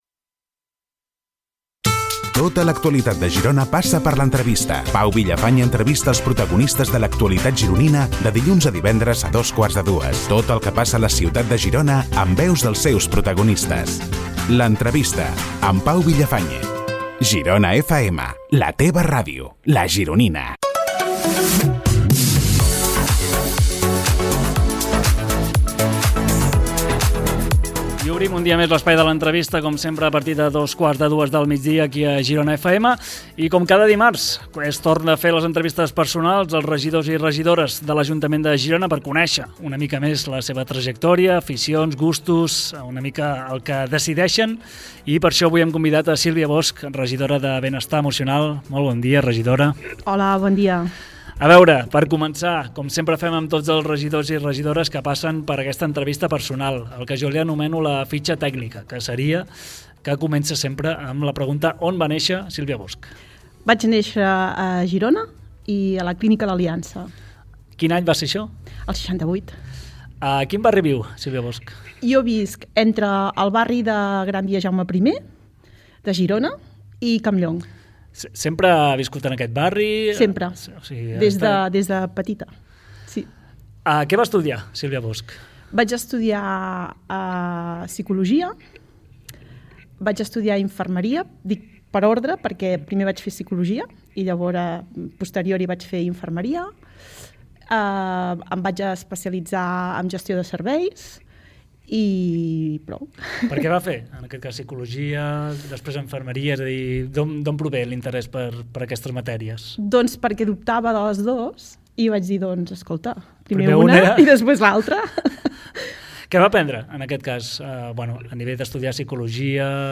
Entrevista personal a la Regidora de Benestar Emocional Sílvia Bosch a GironaFM